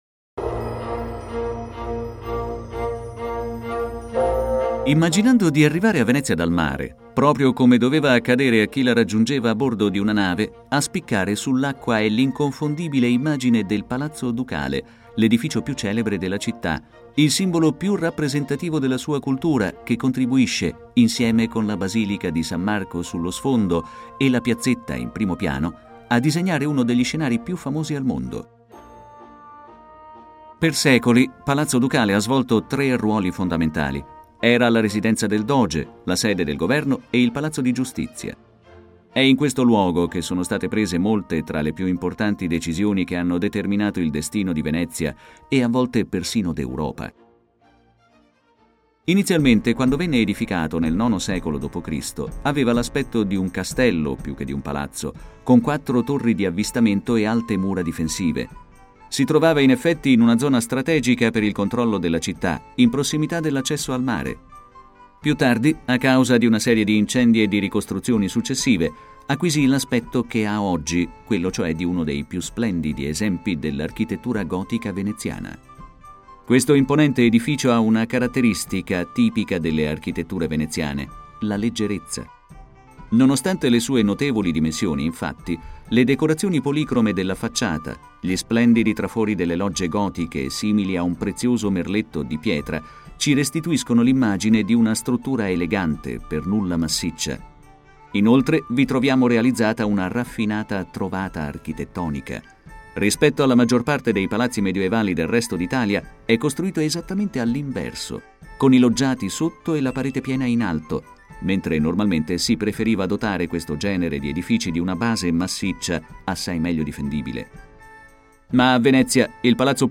Audioguida Palazzo Ducale